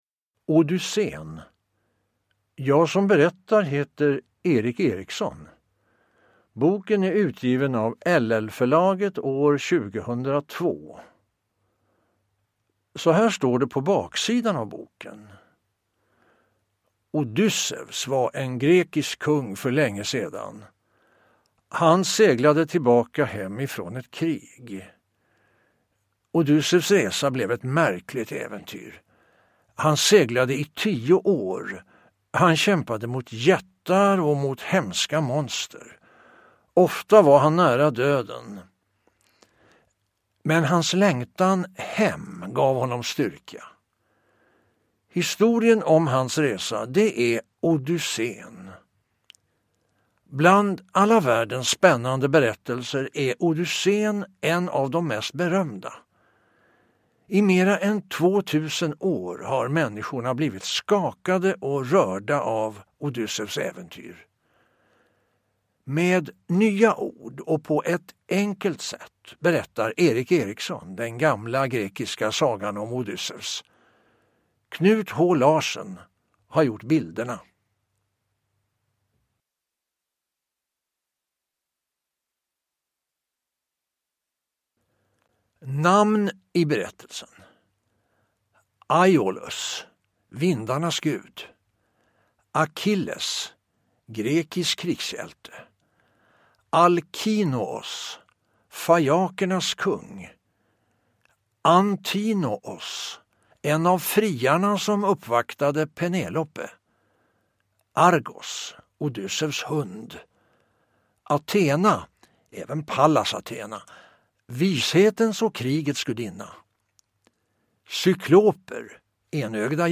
Odysséen / Lättläst / Ljudbok